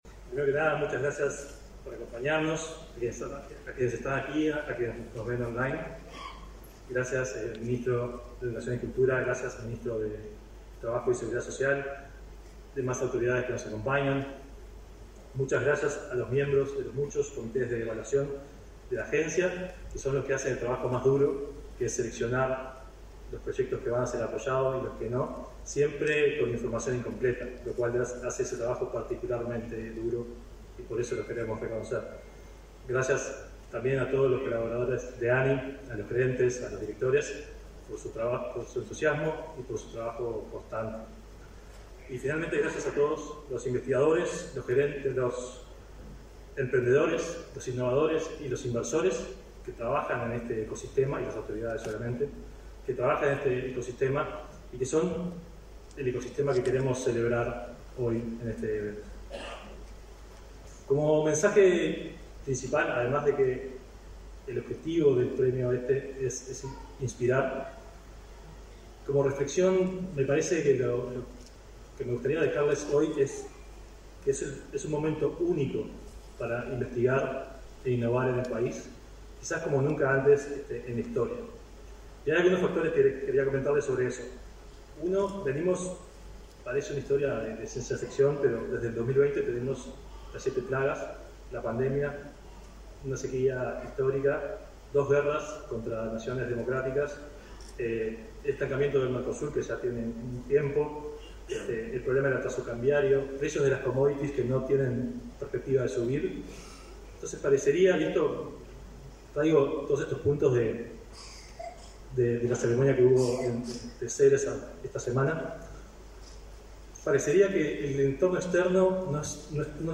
Palabras del presidente de ANII, Flavio Caiafa
El Premio Nacional de Innovación (Nova), otorgado por la Agencia Nacional de Investigación e Innovación (ANII), fue entregado, este 30 de noviembre.